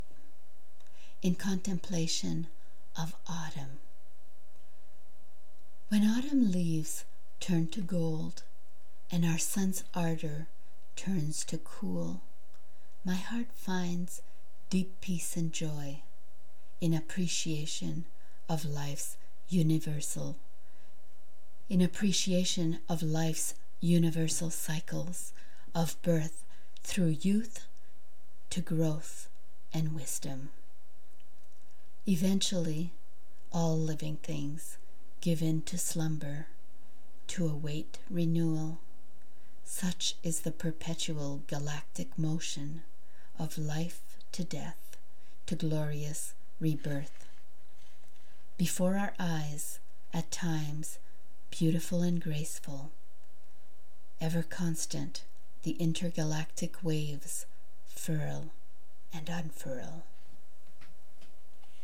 Read on air by invitation  ~  September 8, 2021  'WORDS & MUSIC'